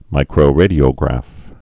(mīkrō-rādē-ō-grăf)